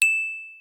04_Twinkle.ogg